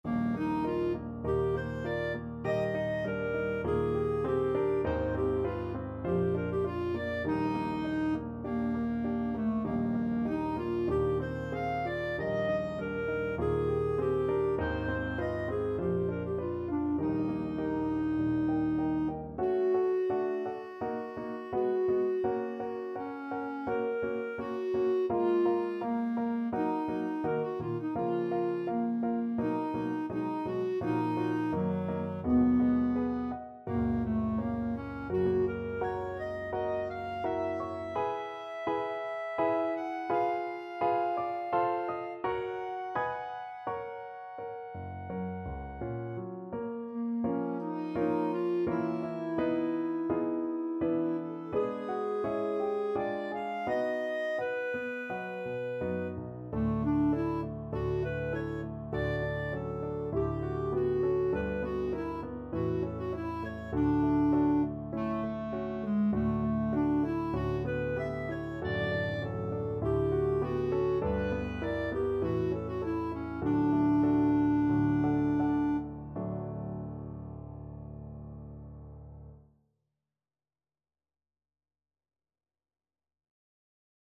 4/4 (View more 4/4 Music)
Moderato
Classical (View more Classical Clarinet Music)